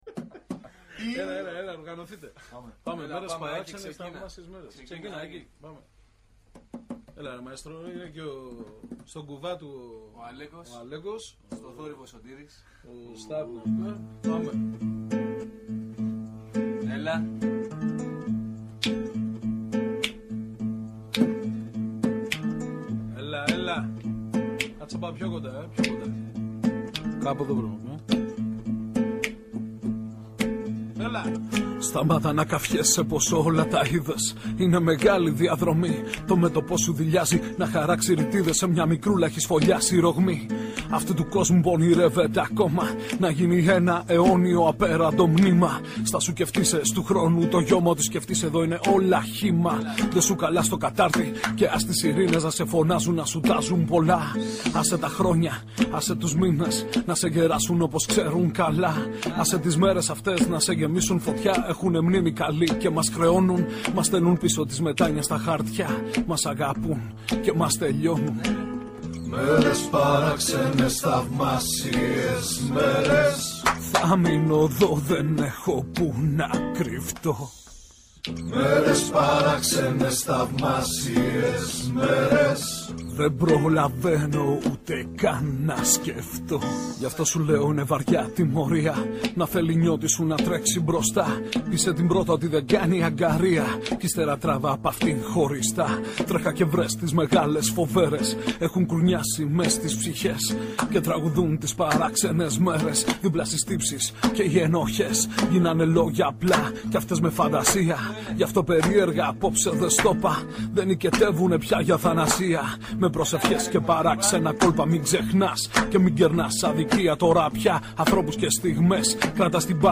Μια εκπομπή για το ντοκιμαντέρ και τους δημιουργούς του στο Α’ Πρόγραμμα της ΕΡΤ.